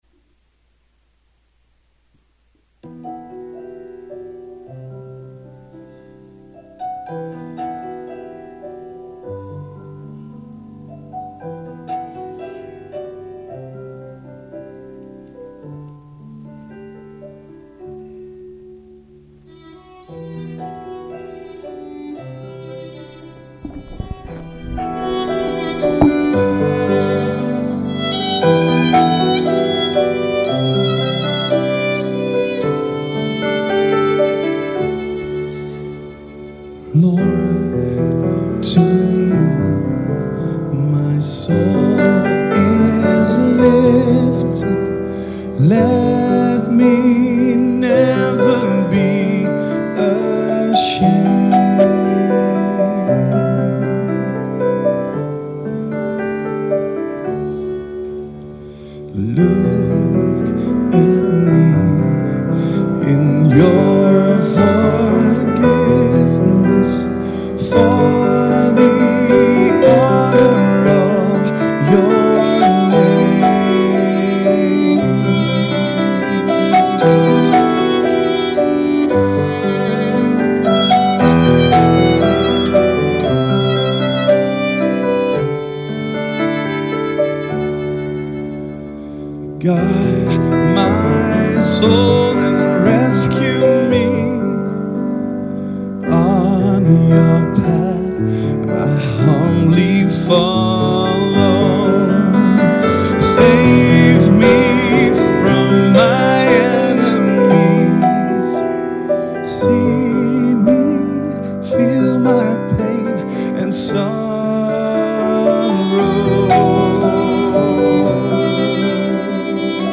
Song: Lord to You My Soul Is Lifted
Reading: I Thessalonians 3: 9-13